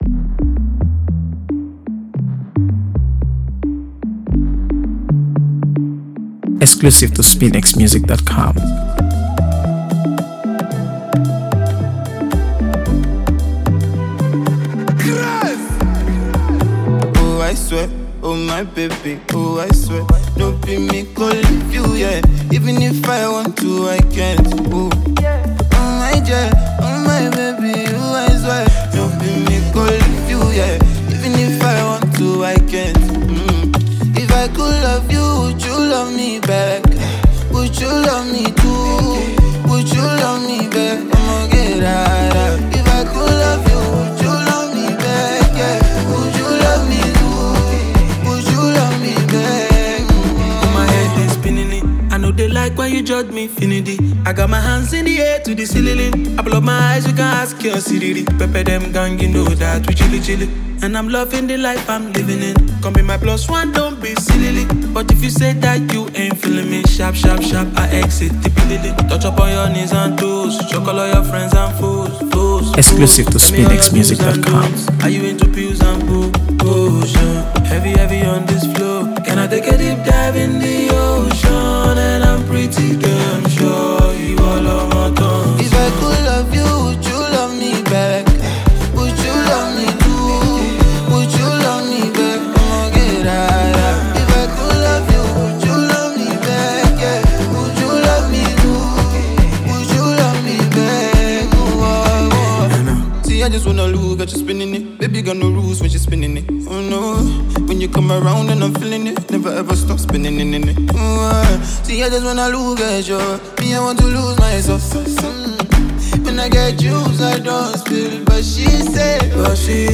AfroBeats | AfroBeats songs
is a smooth, emotionally charged song